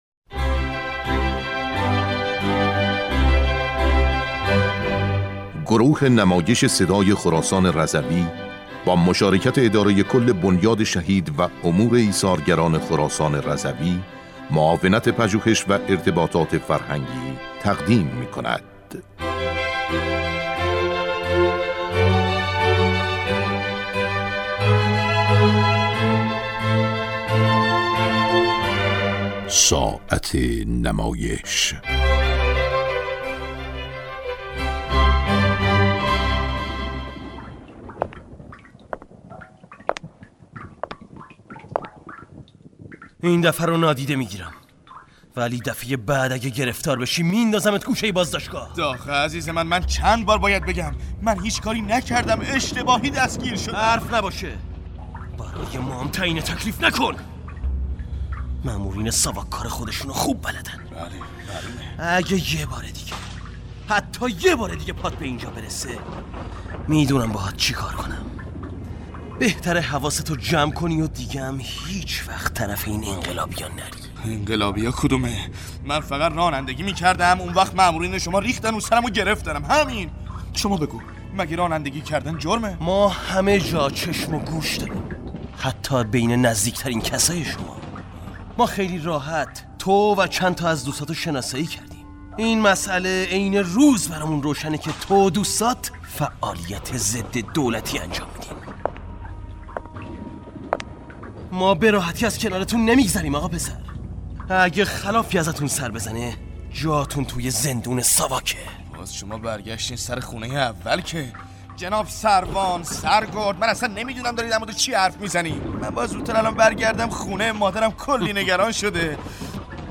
نمایشنامه رادیویی خادم بهشت